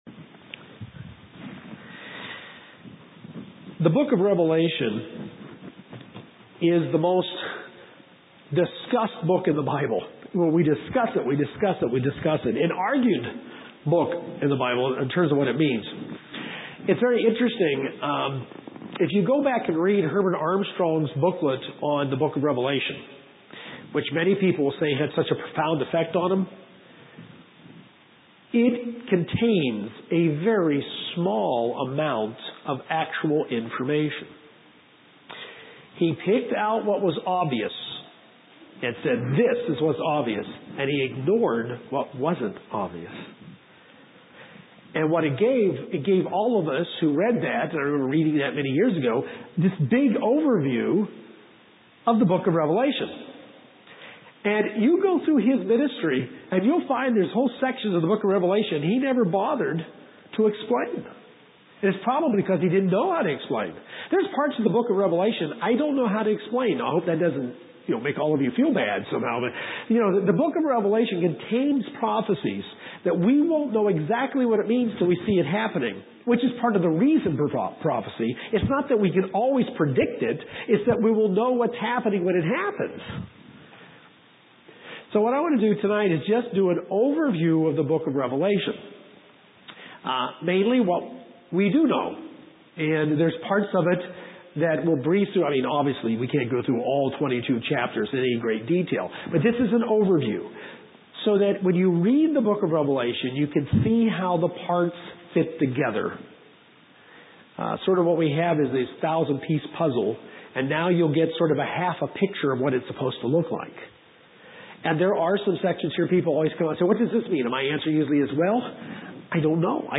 This sermon was given at the Kerrville, Texas 2010 Feast site.